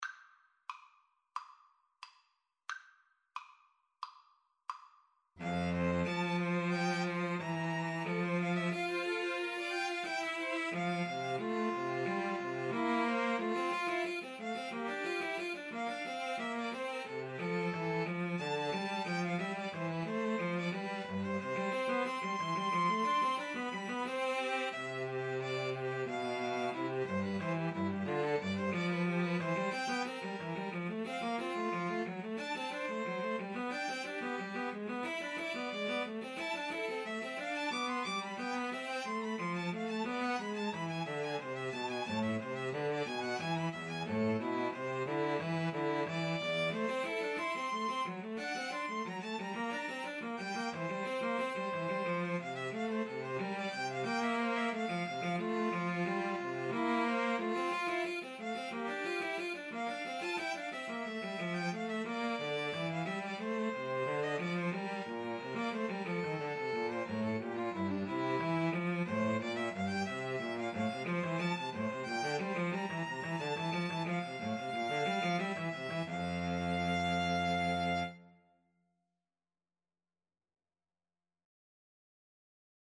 OboeViolinCello
F major (Sounding Pitch) (View more F major Music for Mixed Trio )
Classical (View more Classical Mixed Trio Music)